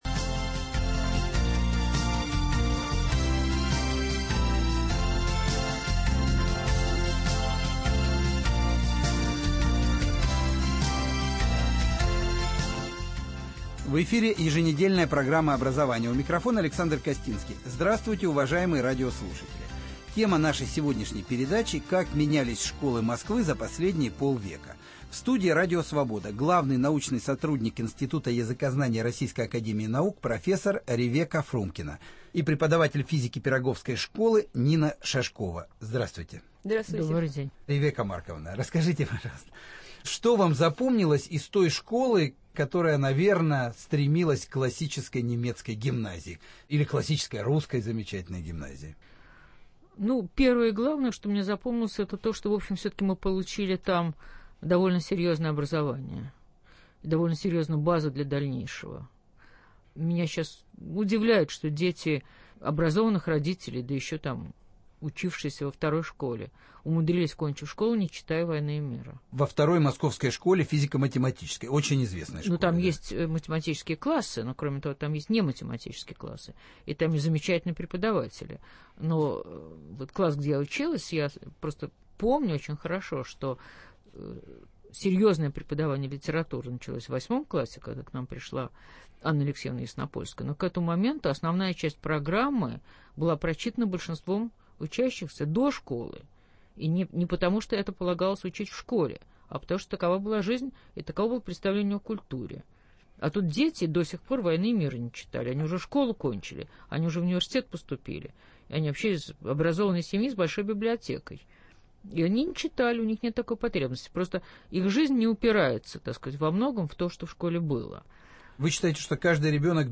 Тема передачи – как менялись школы Москвы за последние полвека. В студии Радио Свобода